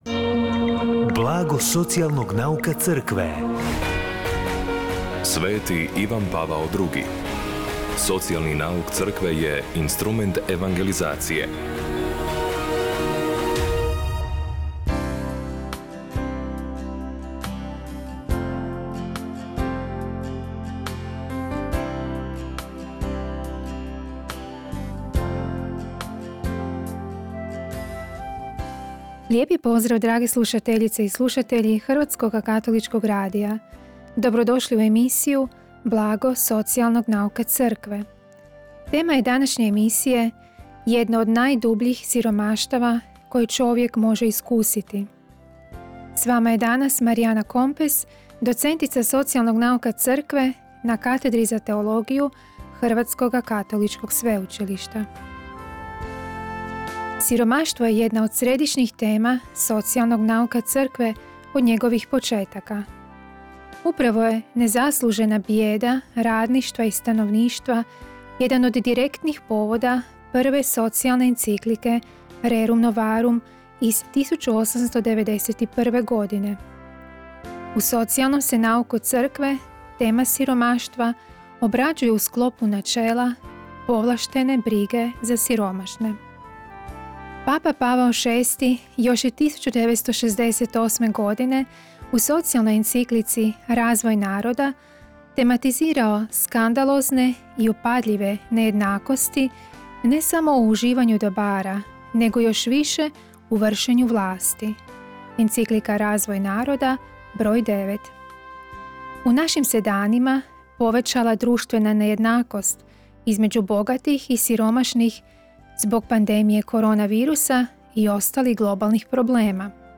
Emisiju na valovima HKR-a „Blago socijalnog nauka Crkve“ srijedom u 16:30 emitira HKR u suradnji s Centrom za promicanje socijalnog nauka Crkve Hrvatske biskupske konferencije.